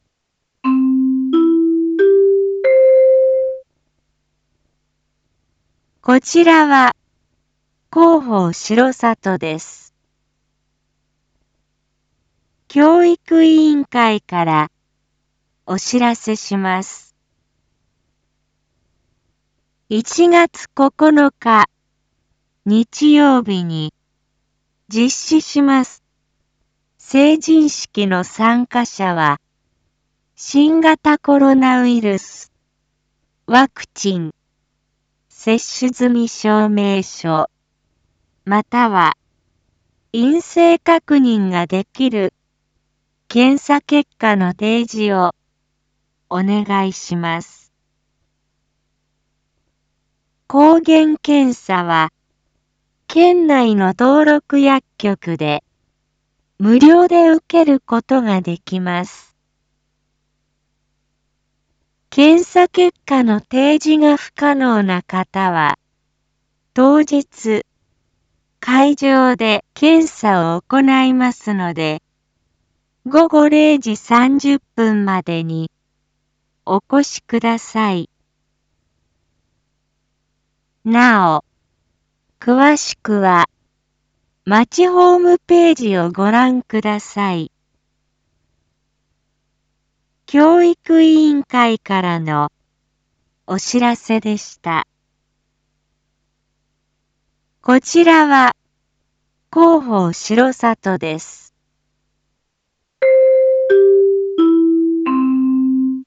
一般放送情報
Back Home 一般放送情報 音声放送 再生 一般放送情報 登録日時：2022-01-08 19:06:47 タイトル：R4.1.8 １９時５分 インフォメーション：こちらは広報しろさとです。 教育委員会からお知らせします。